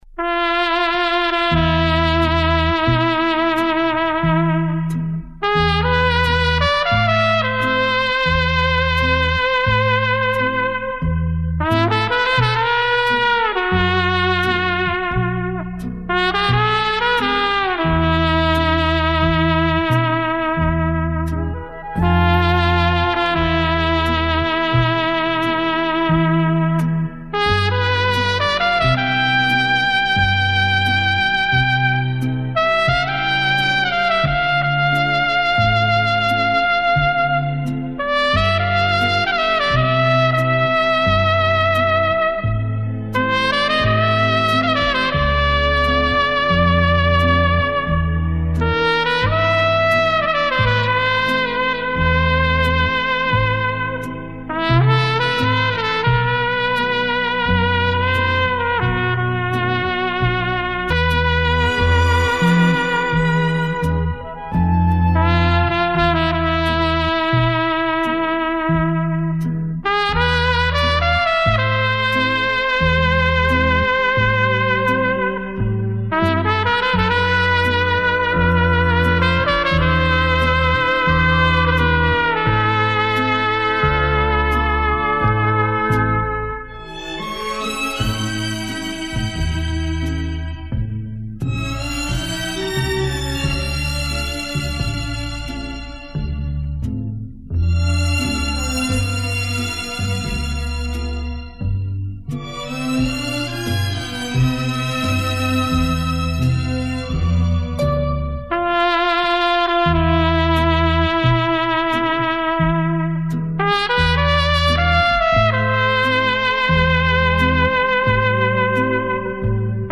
Очень нравится вот это исполнение на трубе